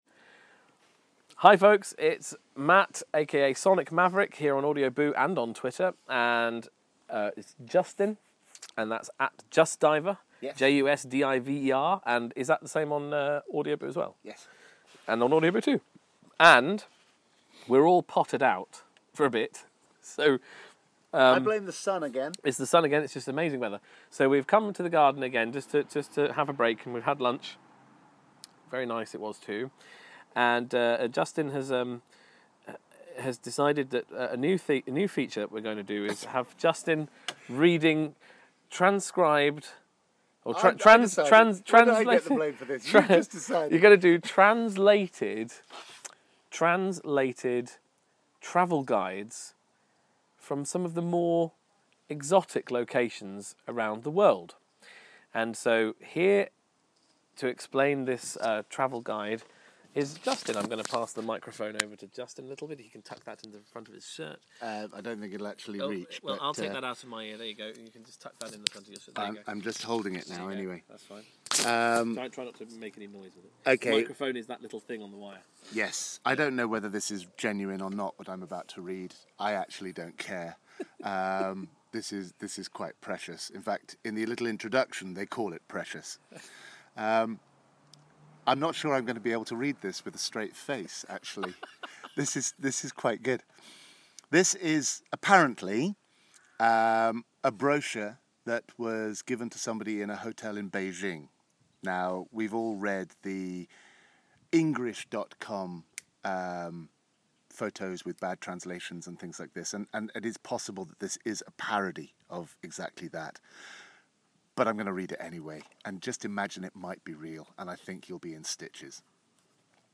reads from a tourist information brochure translated from mandarin!